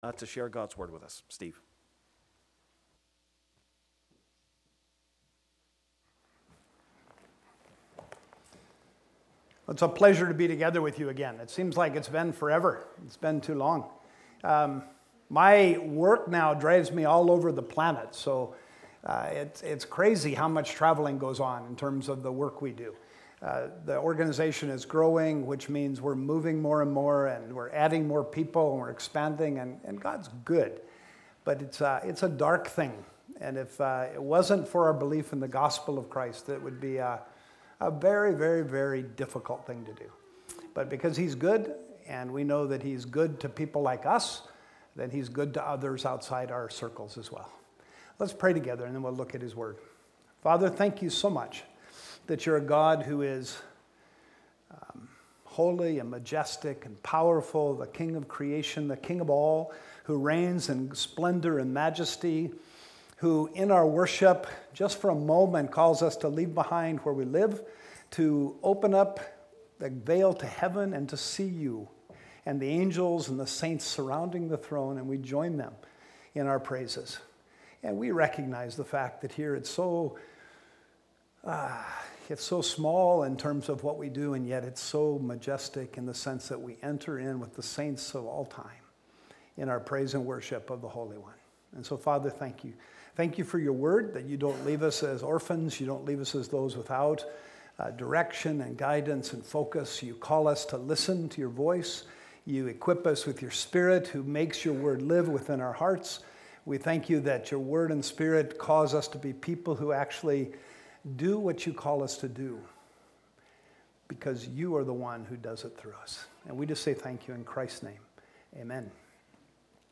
Series: 2019 Sermons (Stand-Alone Message)